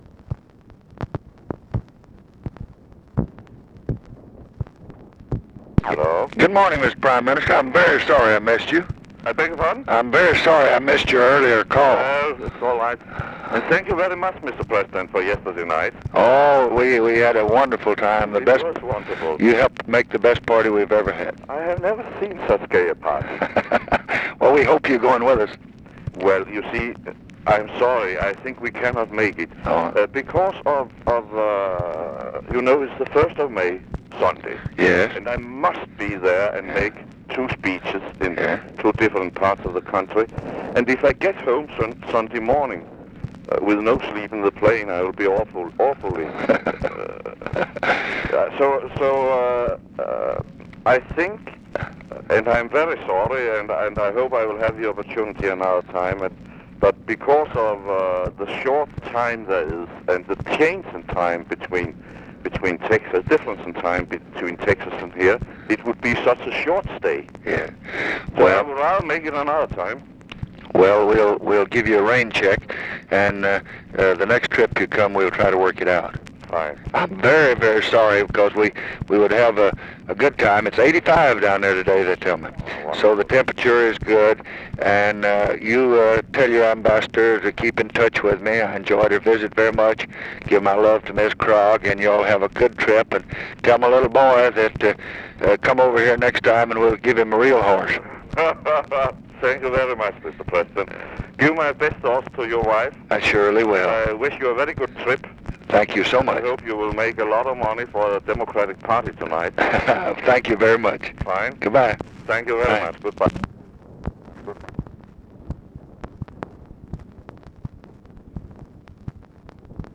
Conversation with JENS OTTO KRAG, April 28, 1966
Secret White House Tapes